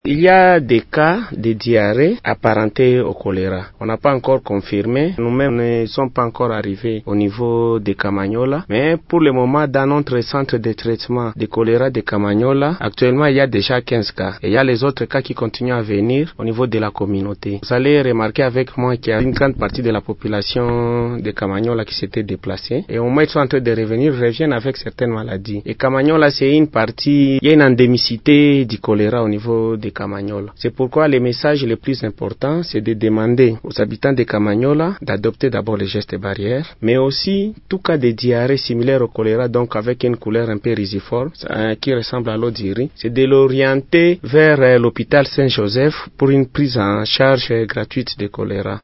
dans un entretien avec Radio Maendeleo ce mardi 25 février 2025.